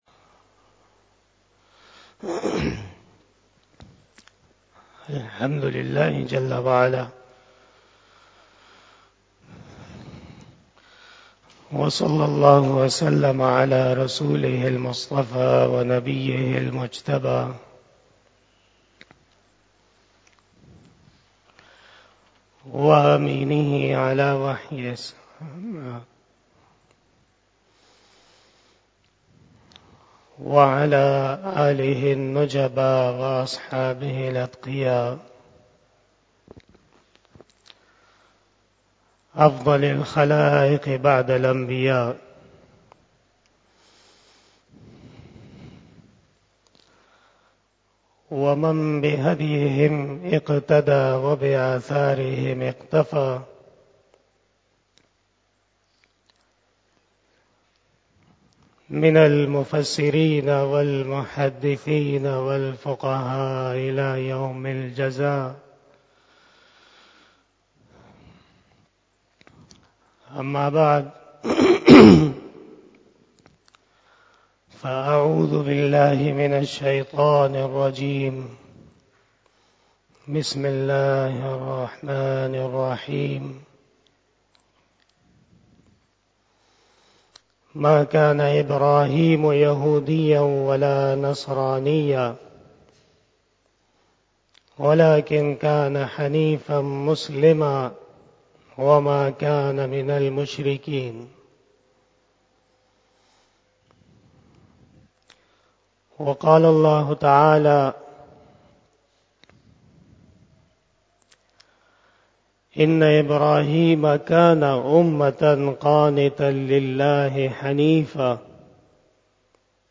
07 BAYAN E JUMMA 17 February 2023 (25 Rajab 1444H)